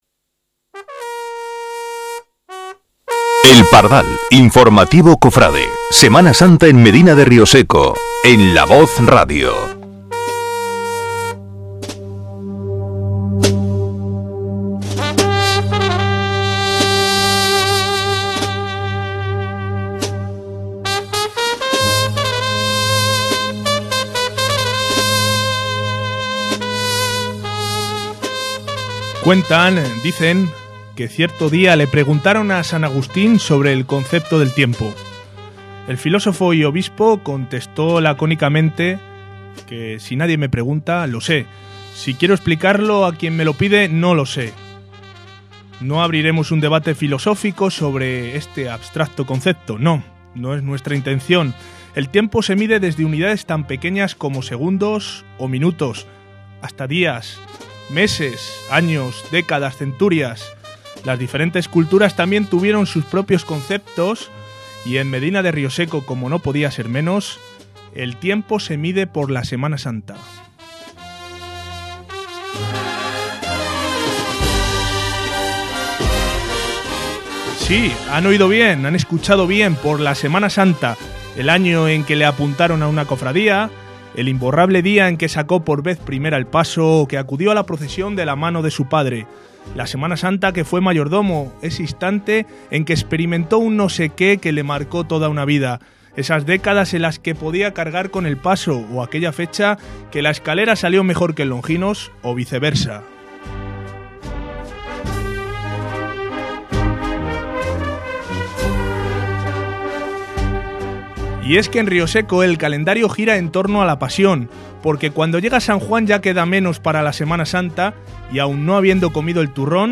Un espacio donde la música cofrade y las noticias tienen especial cabida.